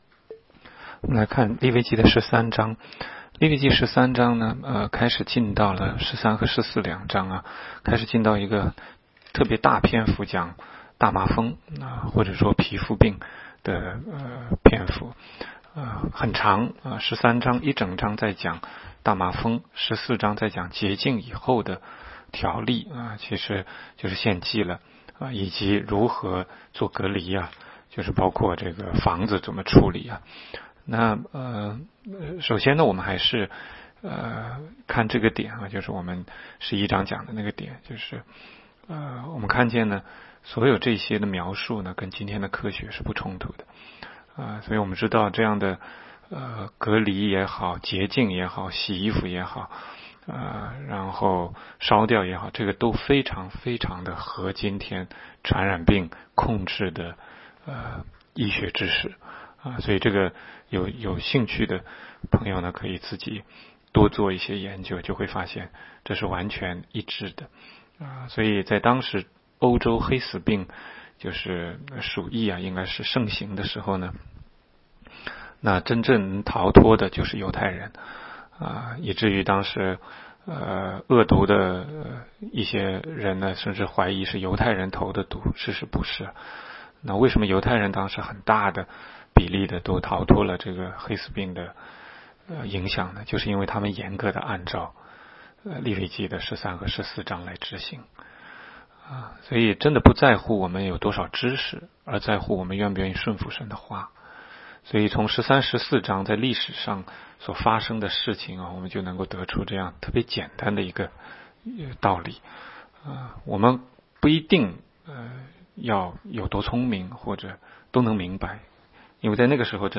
16街讲道录音 - 每日读经-《利未记》13章